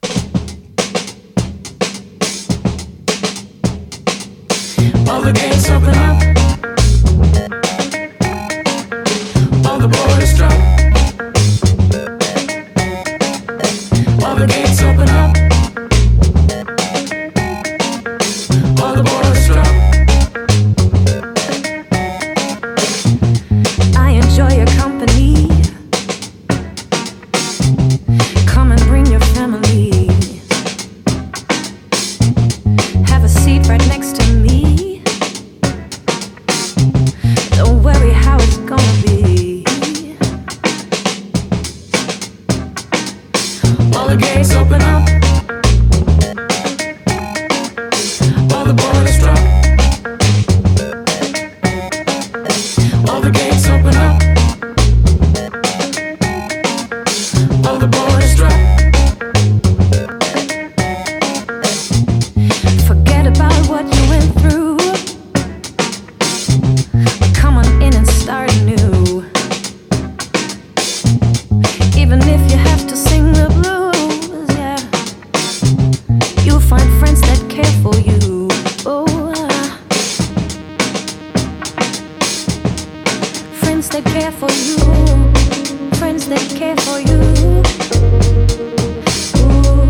KRAUTROCK / SPACE ROCK / PSYCHEDELIC ROCK
スペース・ロック～サイケデリック・ロック〜クラウト・ロックを再構築した2022年作！